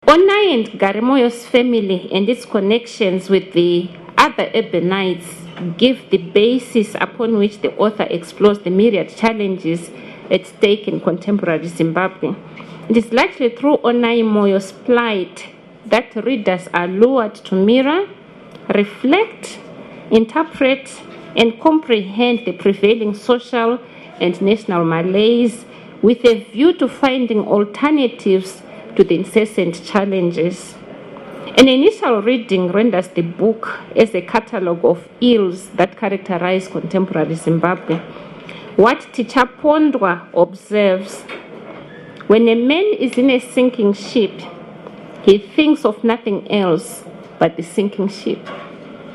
These and other questions debated on 18 September 2008, when a small crowd gathered at Harare's Book Café to discuss Valerie Tagwira's 2007 novel, The Uncertainty of Hope, published by Weaver Press.